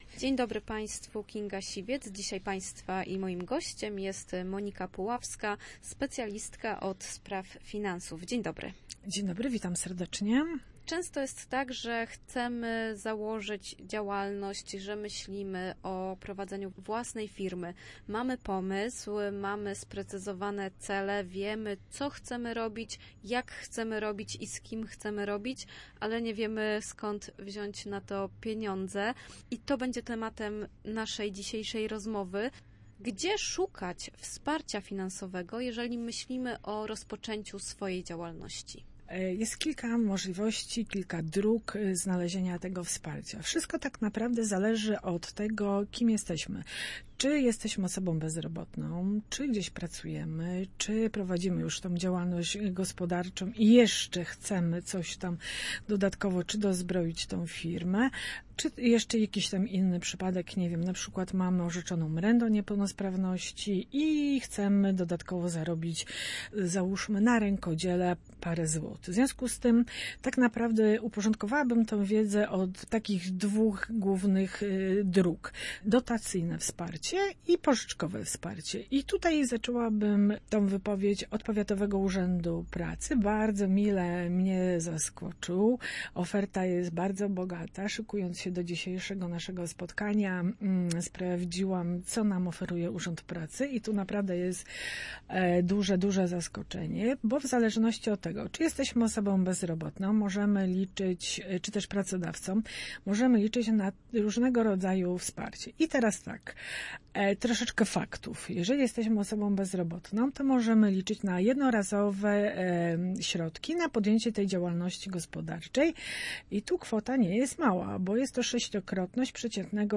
Skąd możemy pozyskać pieniądze, które instytucje udzielą dotacji, a które pożyczą środki na preferencyjnych warunkach? O tym mówiliśmy w dzisiejszej audycji „Przedsiębiorczy Słupsk”.